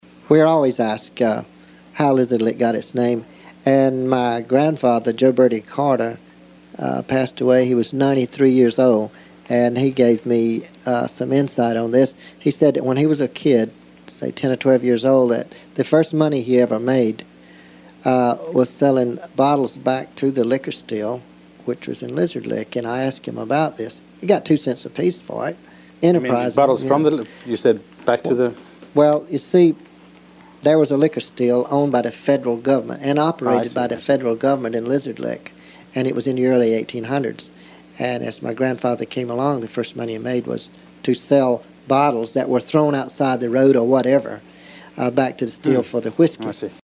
ll02_mayor_speaks.mp3